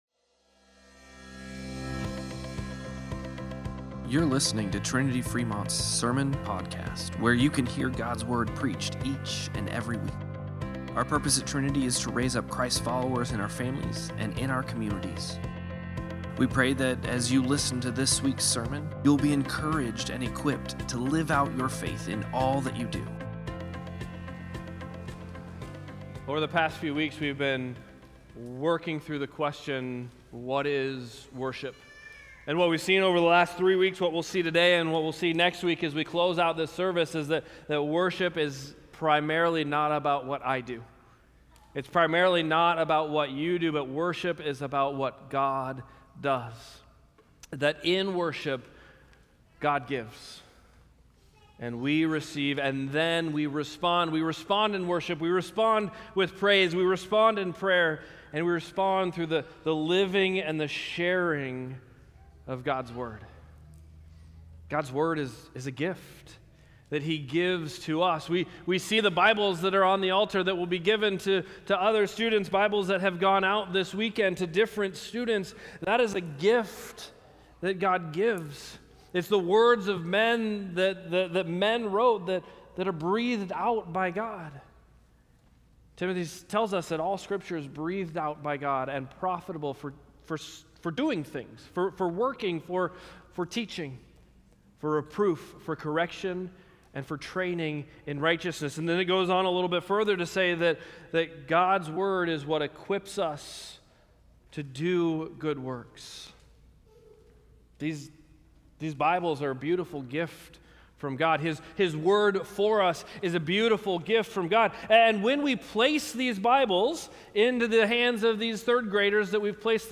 08-17-Sermon-Podcast.mp3